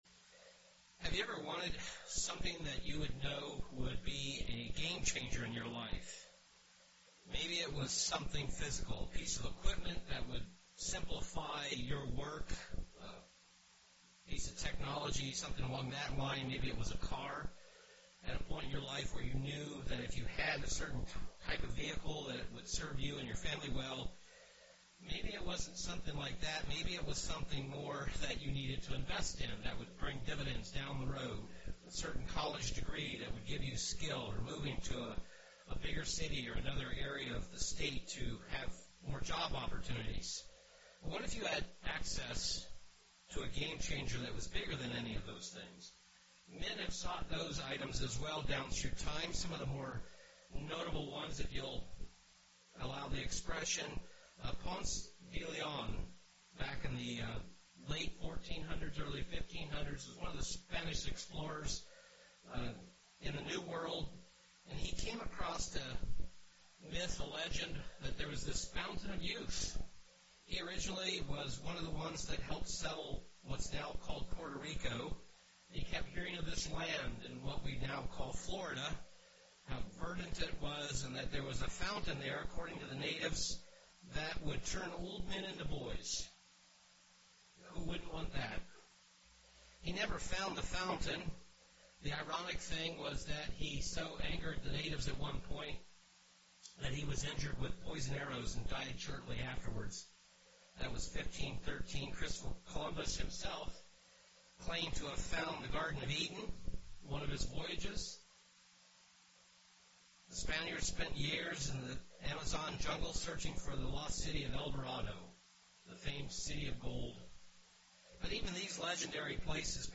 Given in Milwaukee, WI
Feast of Trumpets saints Return of Jesus Christ Firstfruits UCG Sermon Studying the bible?